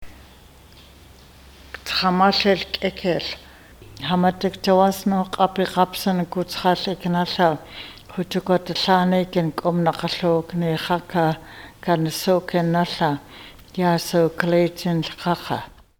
Meal Prayer